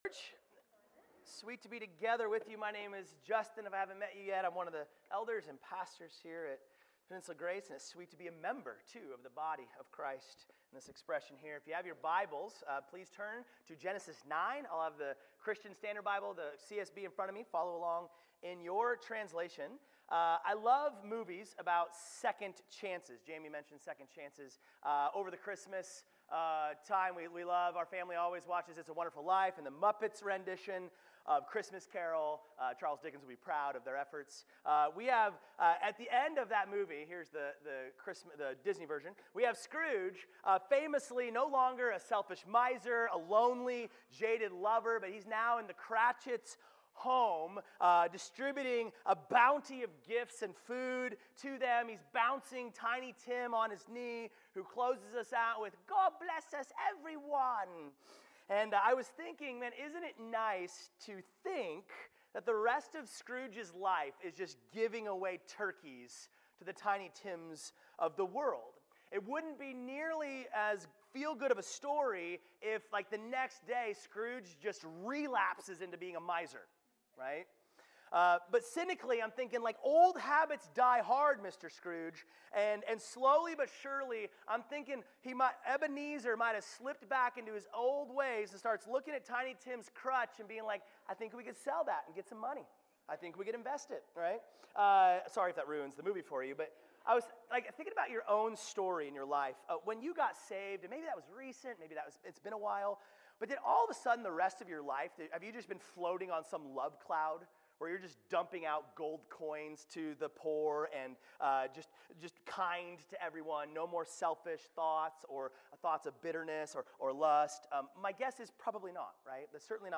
Sermons | Peninsula Grace Church